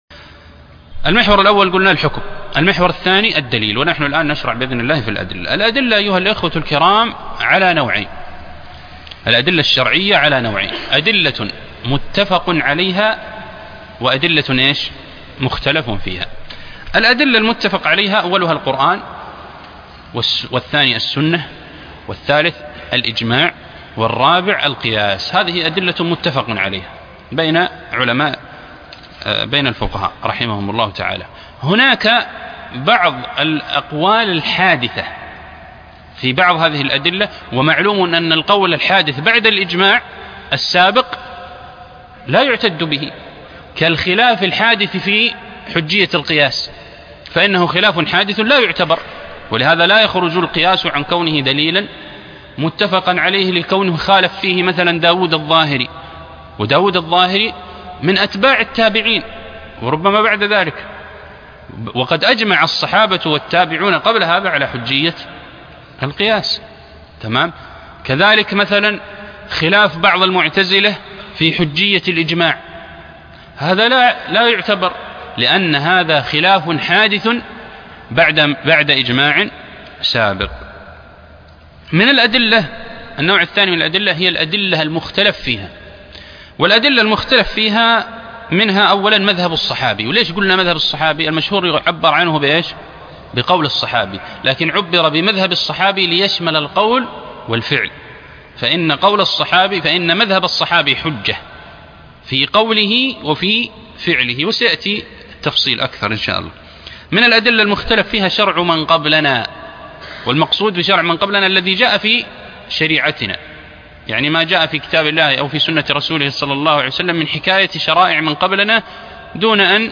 الفقه وأصوله     شروح كتب أصول فقه